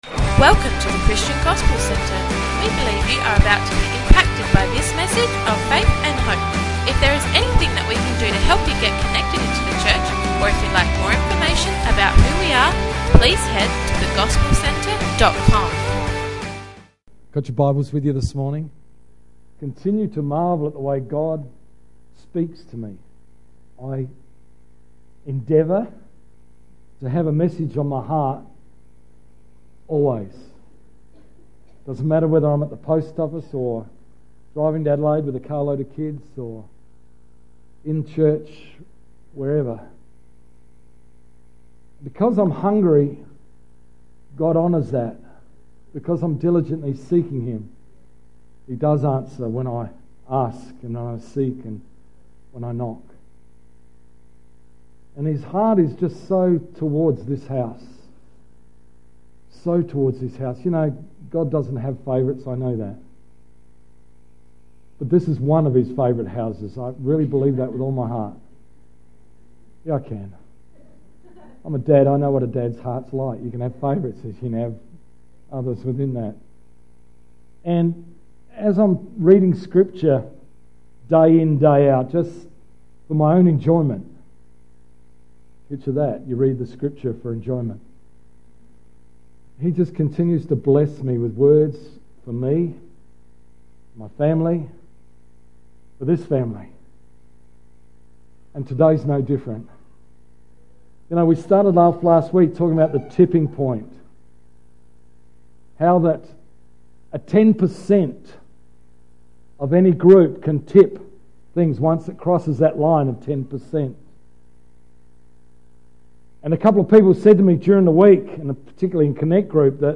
6th March 2016 – Morning Service